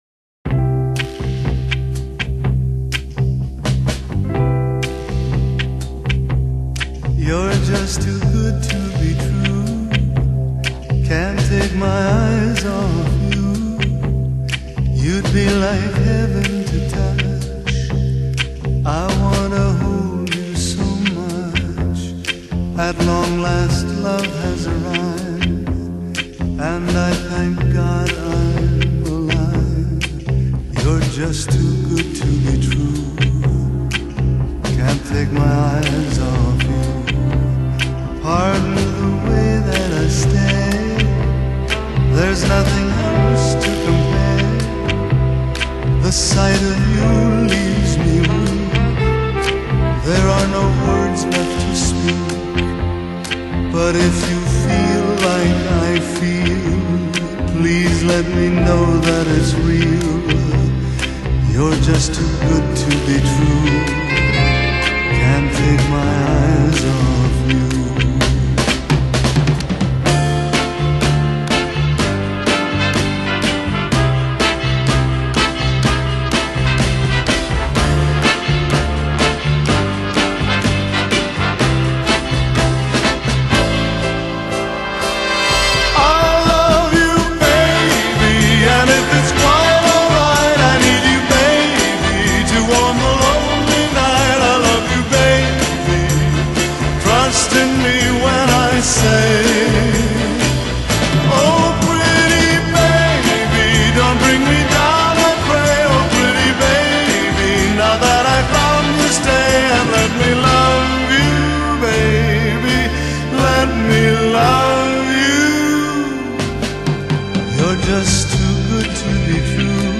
Genre: Pop, Easy Listening